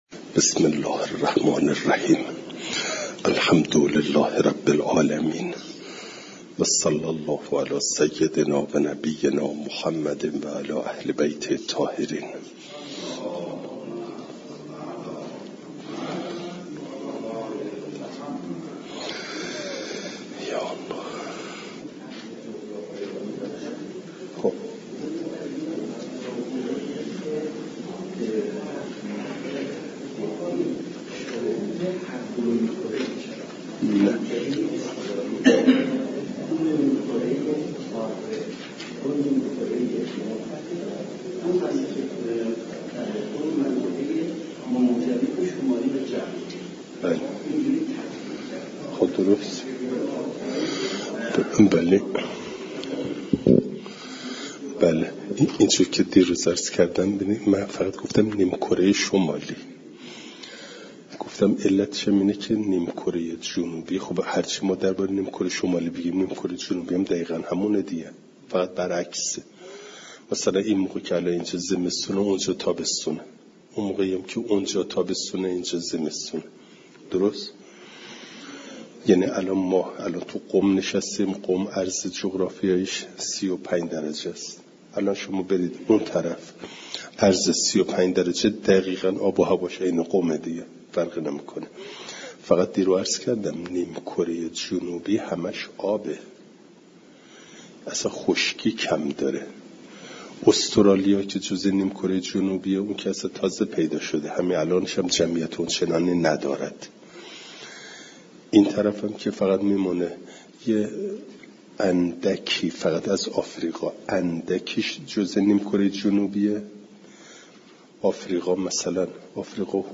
فایل صوتی جلسه صد و پنجاه و نهم درس تفسیر مجمع البیان